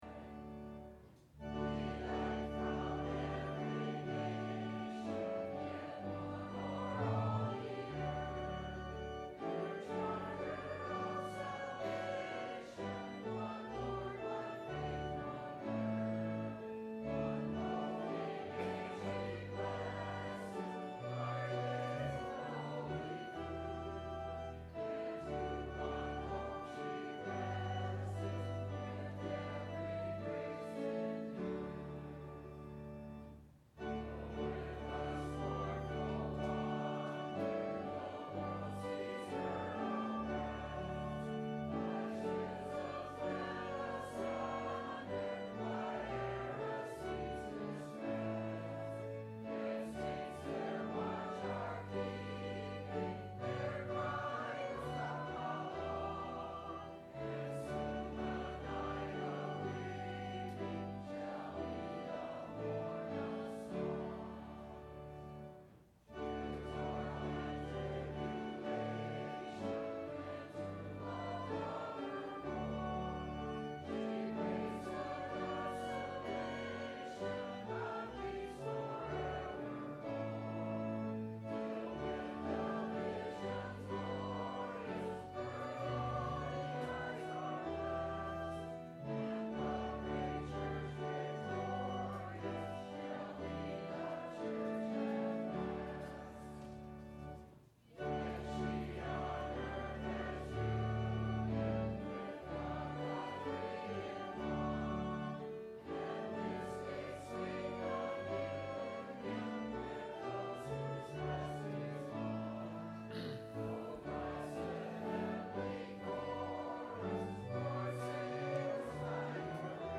Christianity – Religion or Relationship ? – Sermon – September 19 2010